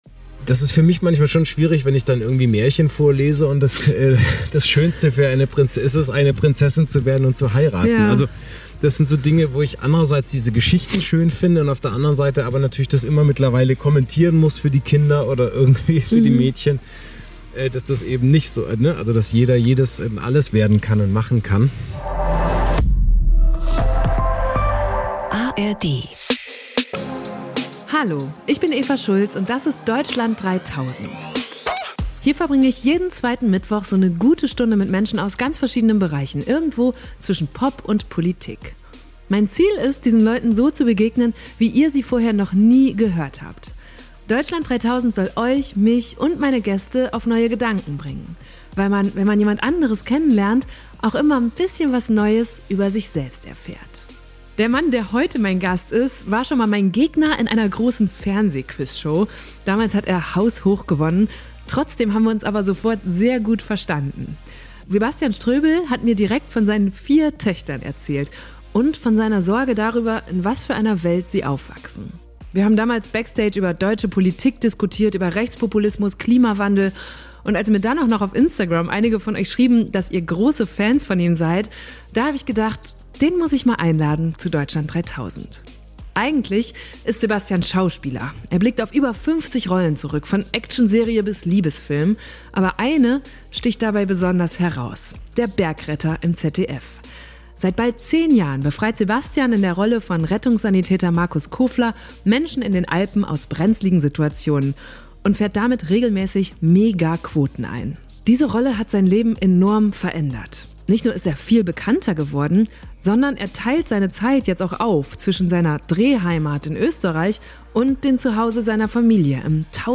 Bei unserem Treffen wollte Sebastian mir Harburg zeigen, einen außen gelegenen Stadtteil von Hamburg, in dem ich vorher noch nie gewesen war.